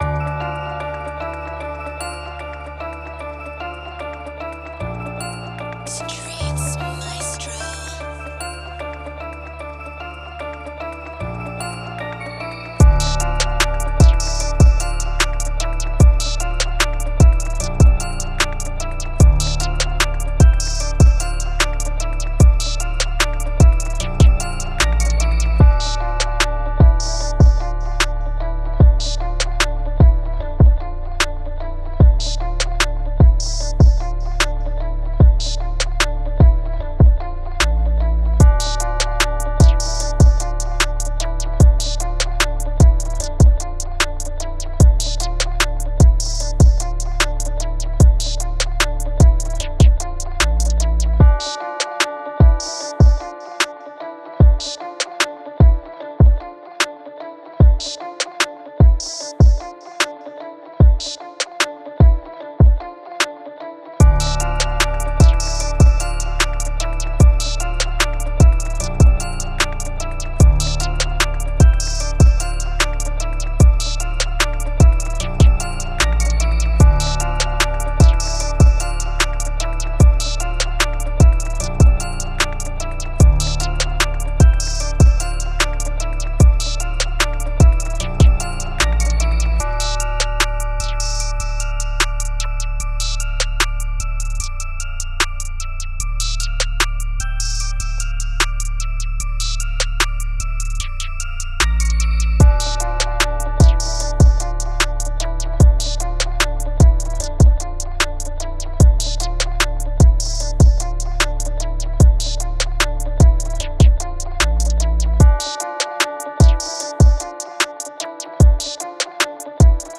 Dramatic Beat
Moods: Dramatic, dark, intense
Genre: Rap
Tempo: 150
BPM 147